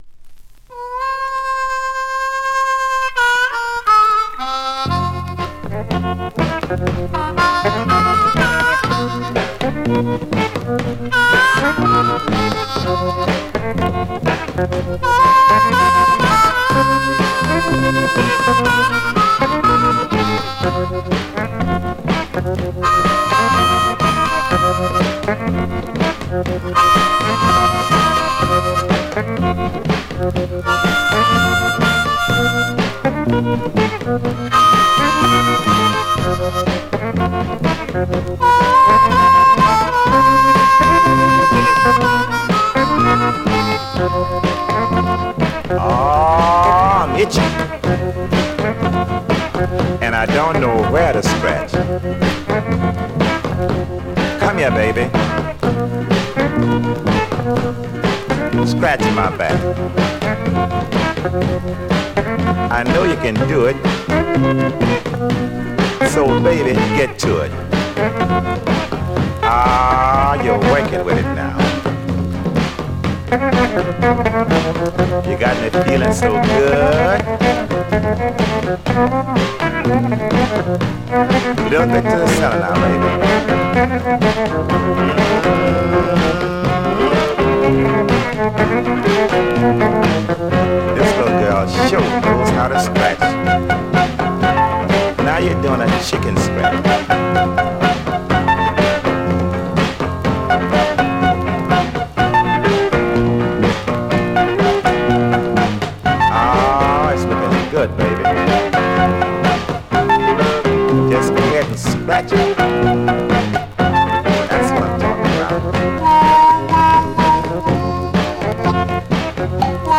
Vinyl has a few light marks plays great .
R&B, MOD, POPCORN